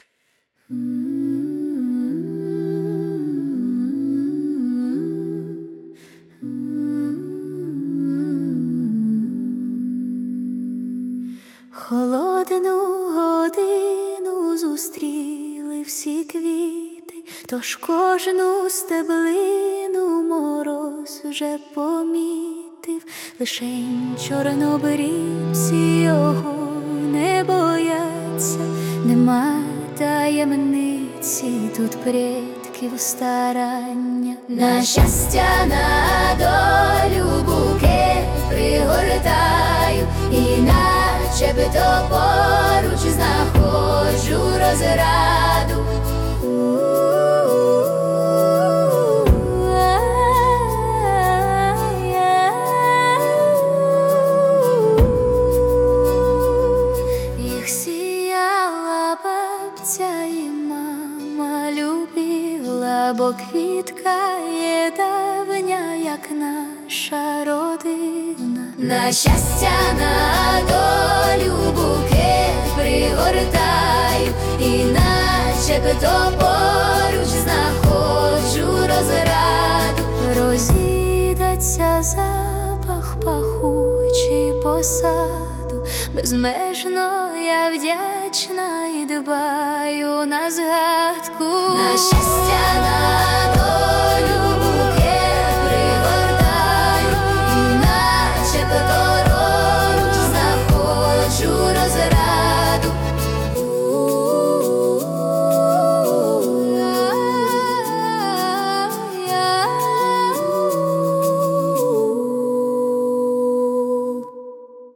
Чарівна і по-домашньому затишна пісня! give_rose give_rose give_rose
Чарівна, щемна та мелодійна пісня.